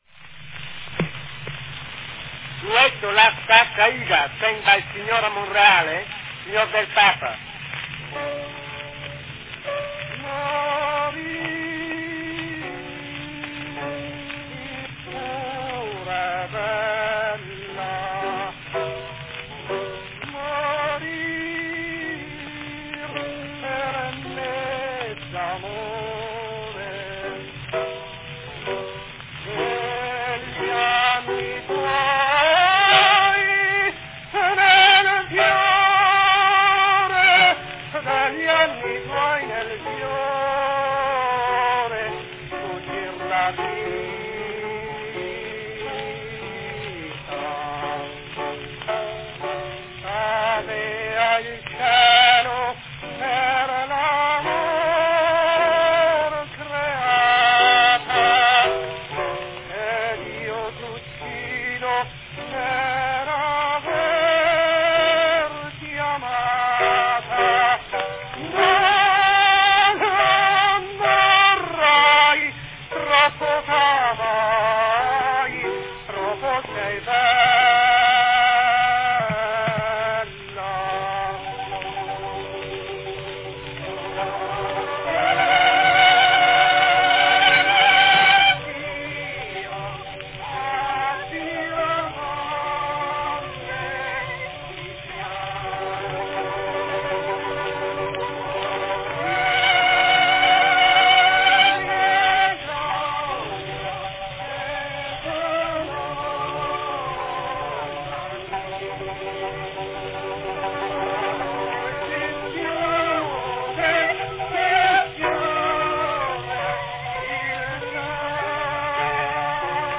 Enjoy a very rare and fine Bettini cylinder from 1899-1900, "O terra, addio" from Aida.
Category Duet
The machine noise heard throughout is typical of Bettini's product.